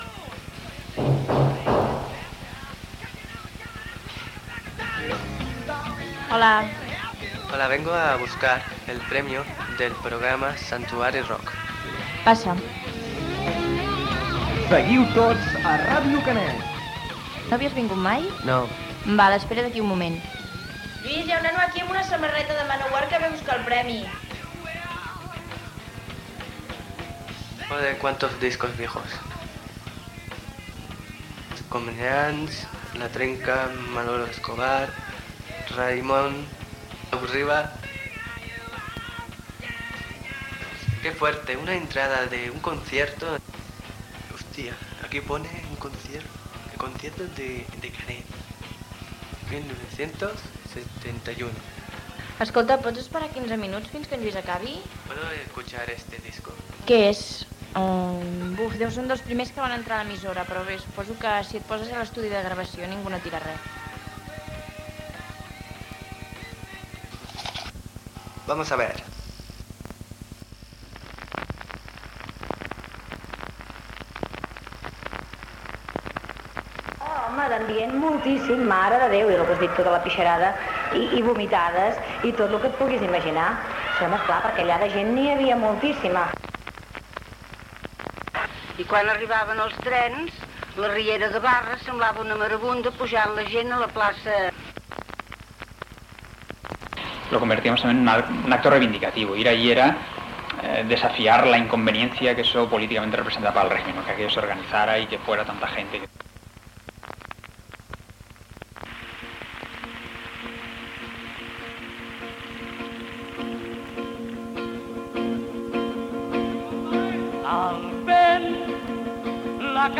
Inici ficcionat, careta del programa, record de la primera edició de les Sis Hores de Cançó de 1971 i de la situació política del moment.